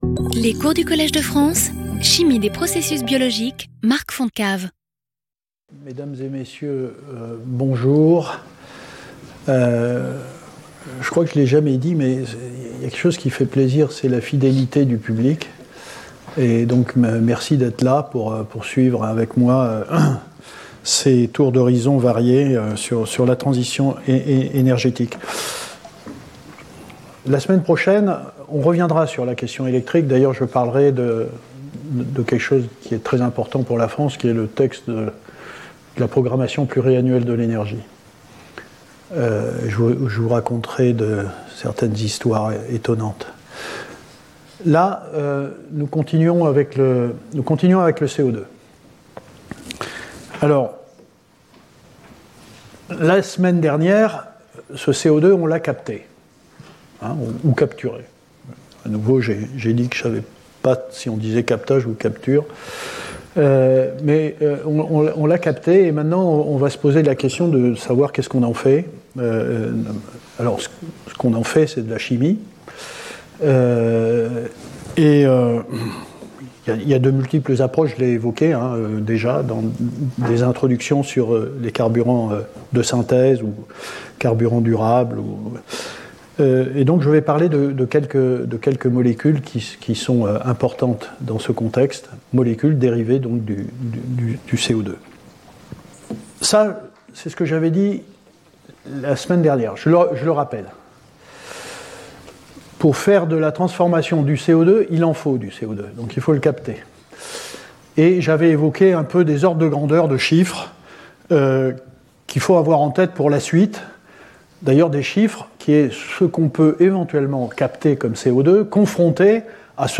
Marc Fontecave Professeur du Collège de France
Cours